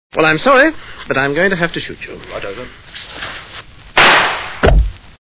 Sfx: Gunshot.